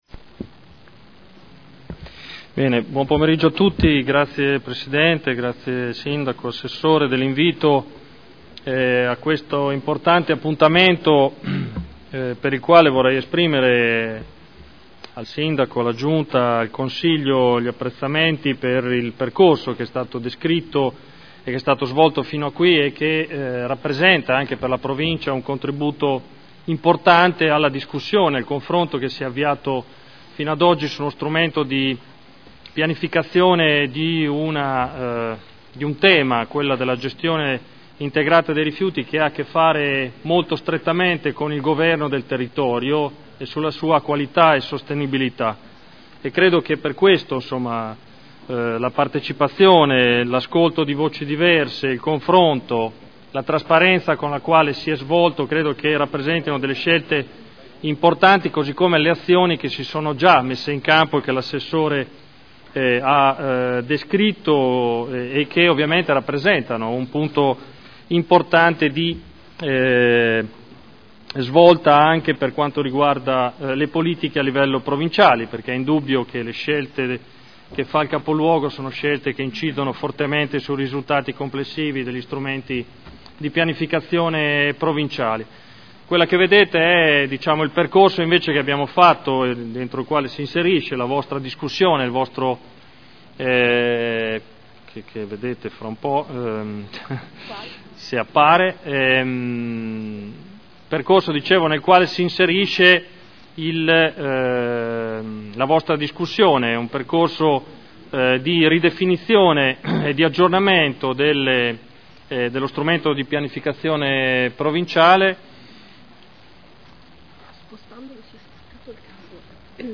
Stefano Vaccari — Sito Audio Consiglio Comunale